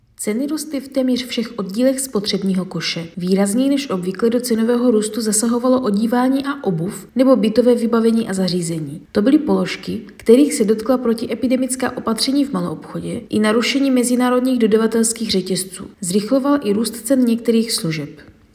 Vyjádření Marka Rojíčka, předsedy ČSÚ, soubor ve formátu MP3, 667.39 kB